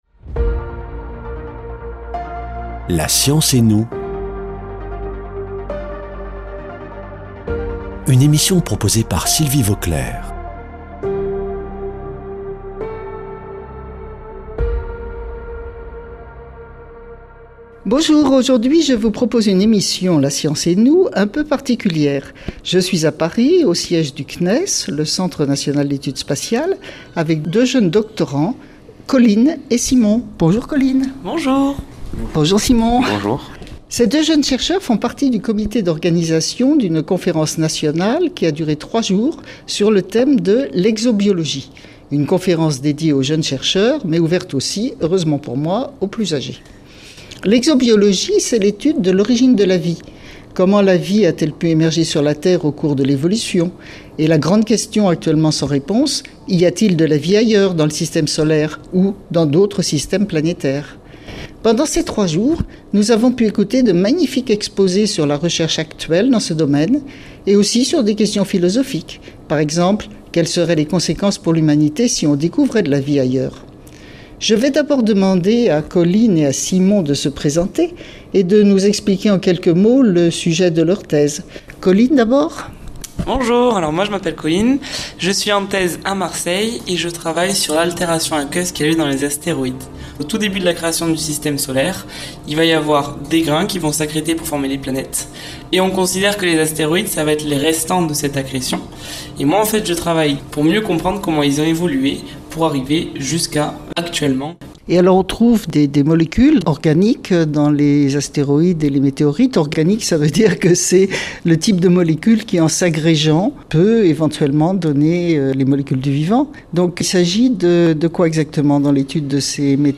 Une émission présentée par Sylvie Vauclair Astrophysicienne